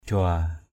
/ʥʊa:/